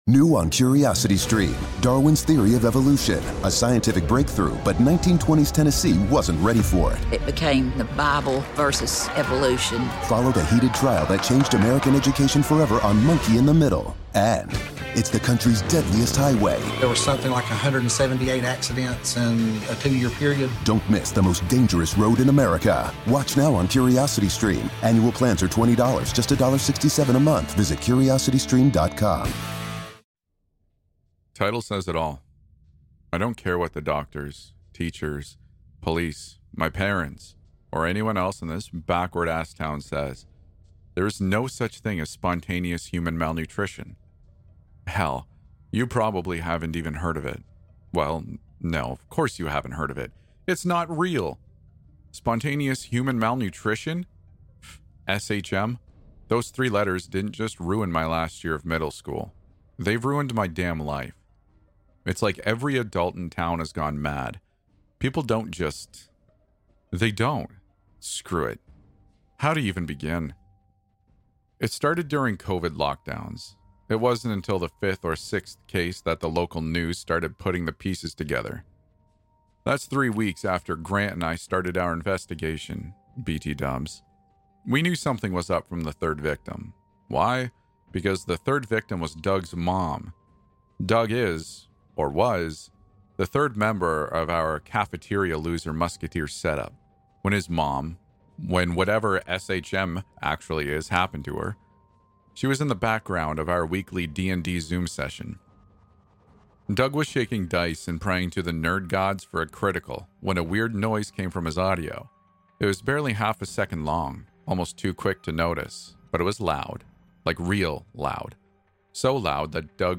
Episode 175 | Spontaneous Human Malnutrition | Creepy NoSleep Stories